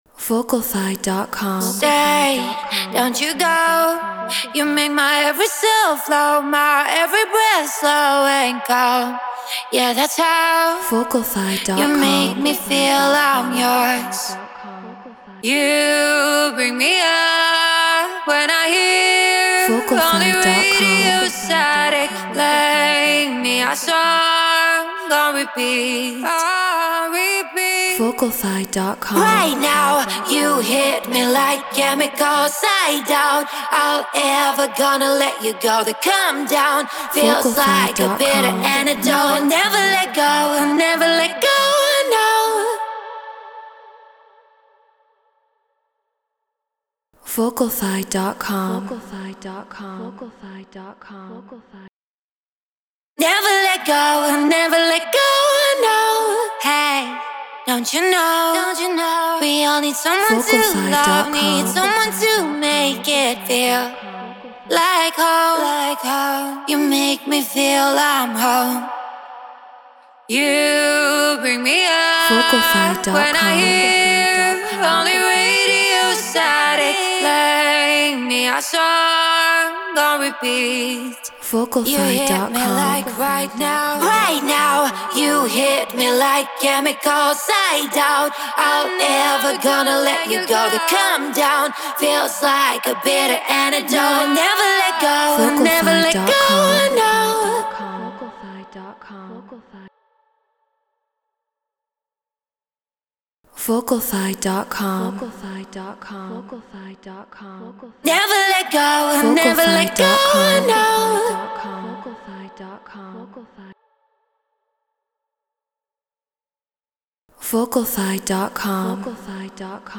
Eurodance 140 BPM Bmin
Human-Made
Brauner VMX Apogee Elements 88 Ableton Live Treated Room